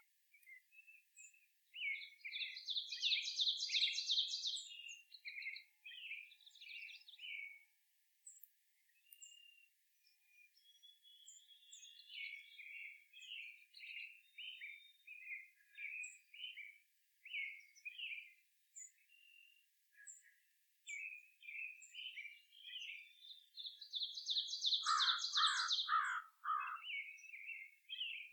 Parulien couronnée quiz sans bruit